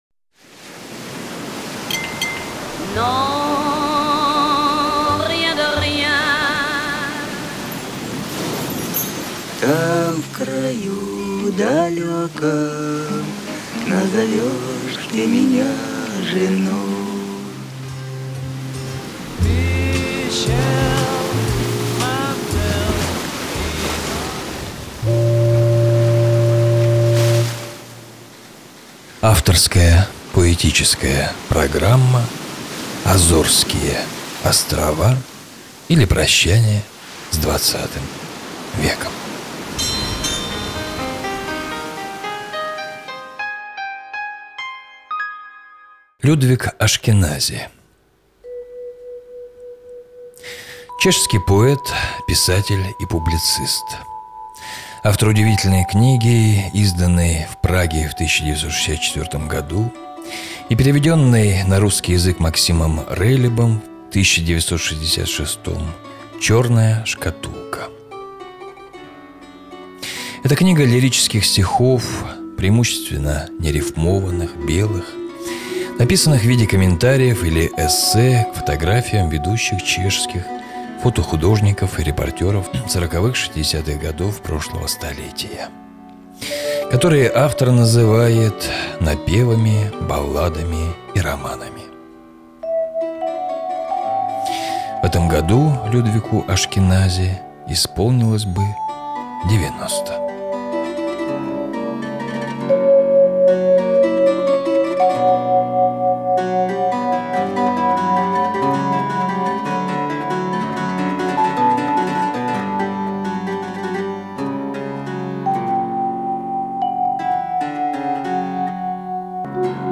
На протяжении достаточно долгого времени, начиная с 1999 года, на разных радиостанциях города* выходили мои авторские поэтические и литературно-музыкальные программы – «АЗОРСКИЕ ОСТРОВА, ИЛИ ПРОЩАНИЕ С ХХ ВЕКОМ…», «ЖАЖДА НАД РУЧЬЁМ», «НА СОН ГРЯДУЩИЙ», «ПолУночный КОВБОЙ», «ПОСЛУШАЙТЕ!».
Музыка – Микаэла Таривердиева